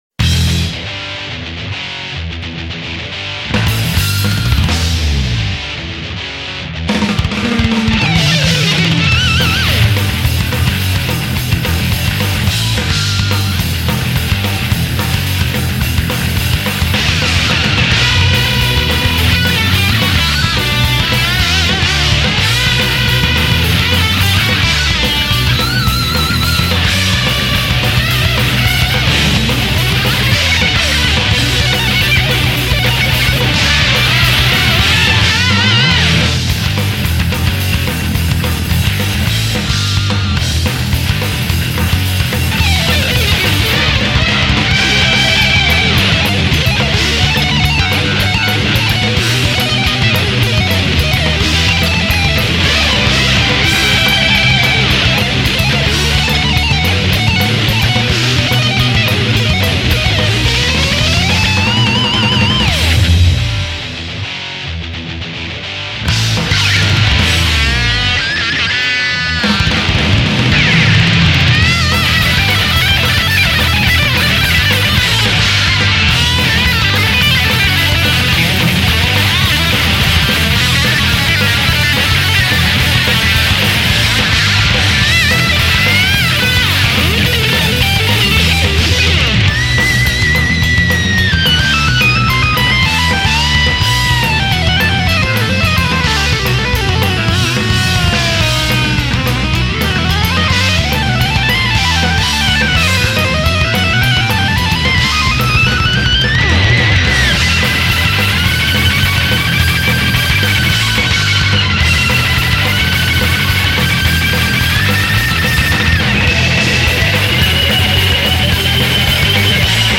을 위해 롱 버전으로 재편곡하여 길게 곡을 쓰셨습니다.